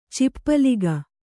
♪ cippaliga